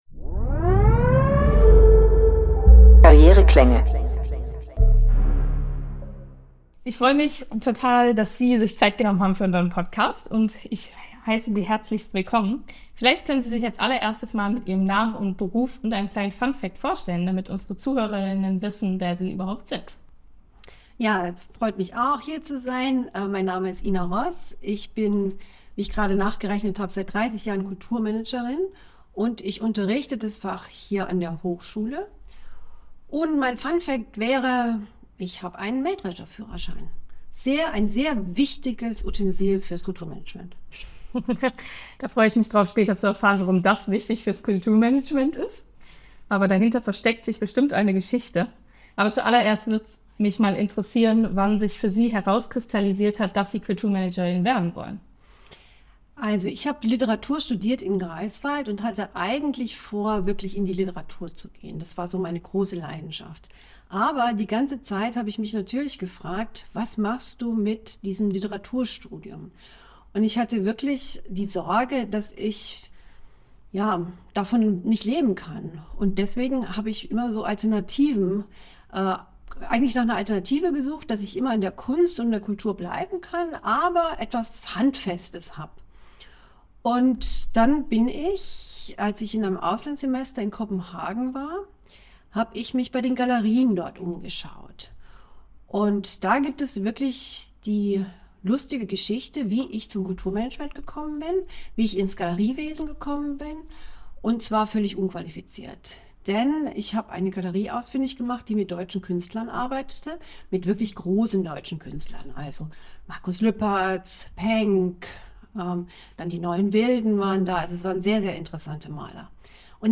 Karriereklänge – Talk